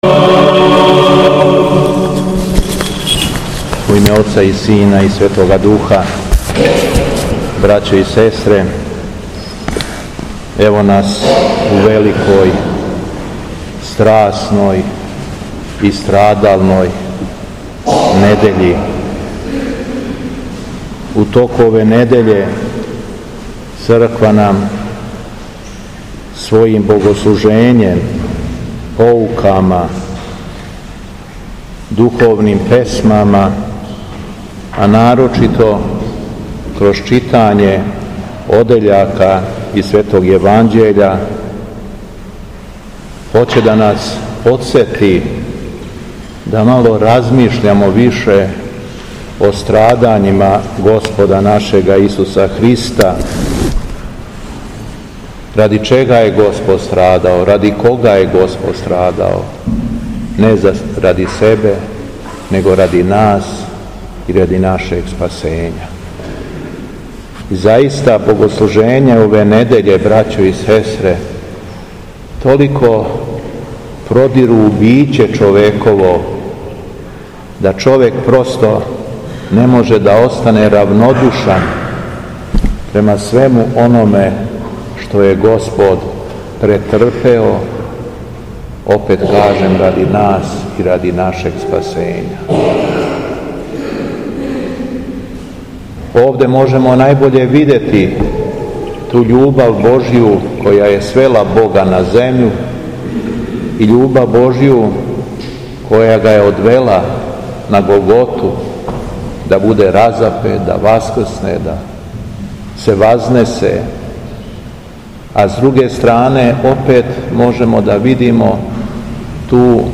Беседа Његовог Високопреосвештенства Митрополита шумадијског г. Јована
После прочитаног Јеванђеља, Високопреосвећени Митрополит се обратио верном народу у надахнутој беседе: